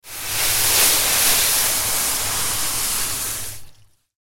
دانلود آهنگ کپسول آتش نشانی از افکت صوتی اشیاء
دانلود صدای کپسول آتش نشانی از ساعد نیوز با لینک مستقیم و کیفیت بالا
جلوه های صوتی